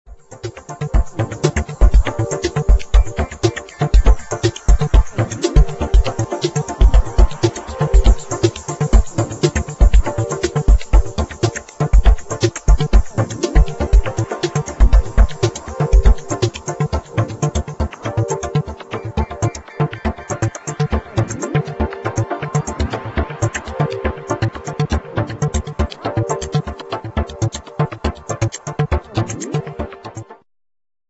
2000 exciting medium instr.